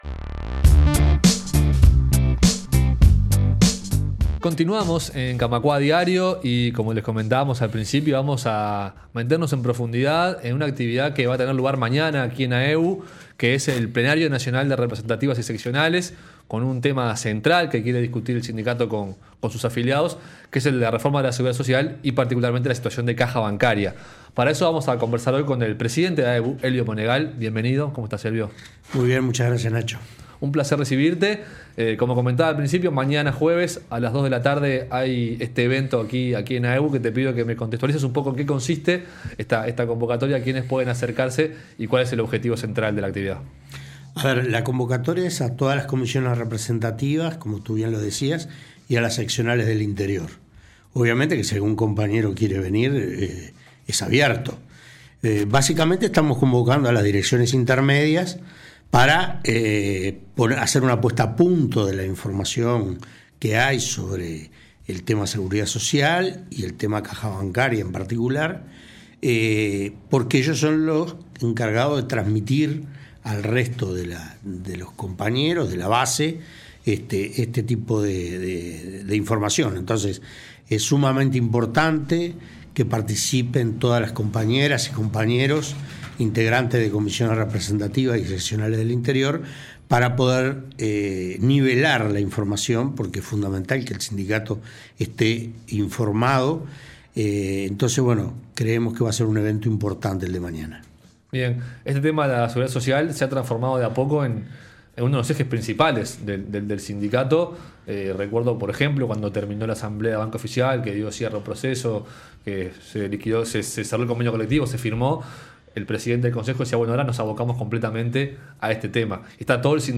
en entrevista este martes con Camacuá Diario.